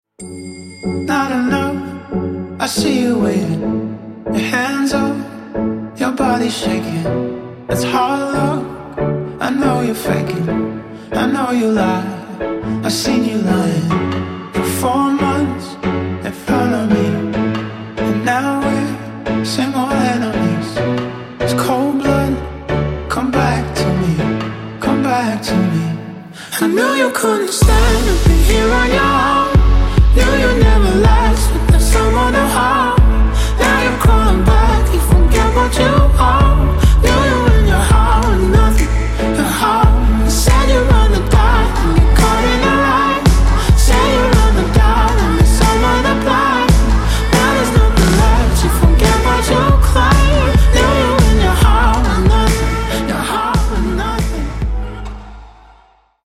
Жанры: Дип-хаус, Даунтемпо, Электроника.